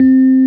SM64UnusedVibraphoneInst.wav.mp3